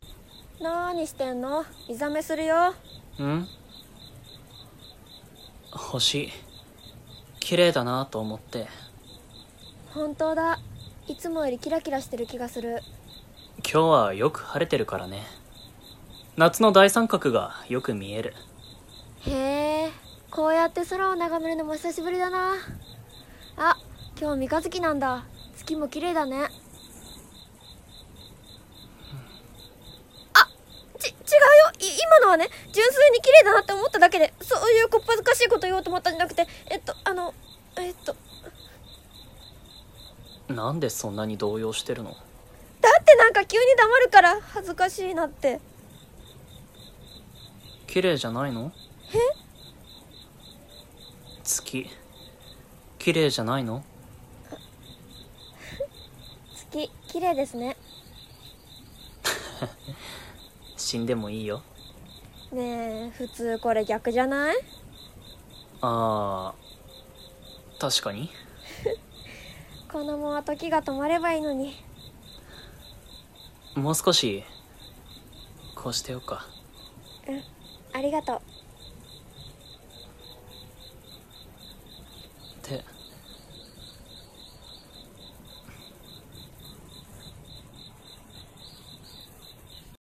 【声劇】月が綺麗ですね。【掛け合い】